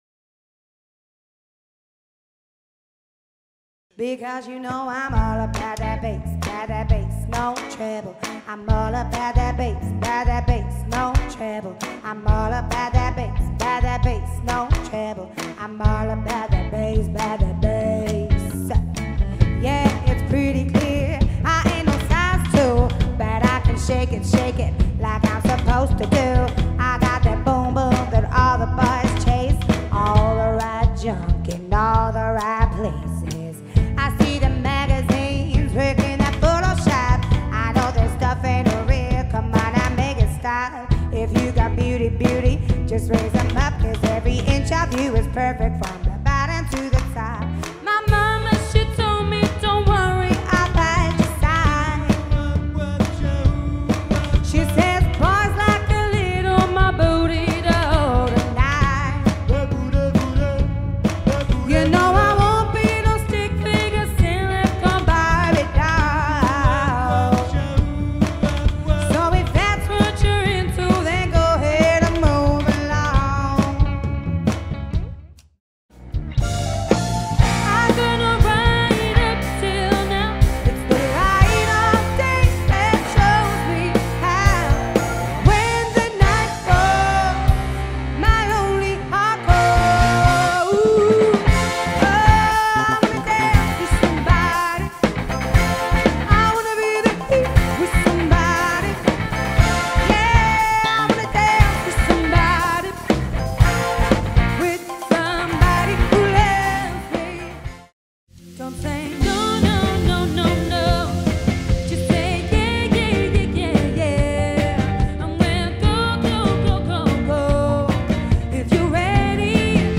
function band